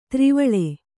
♪ tri vaḷe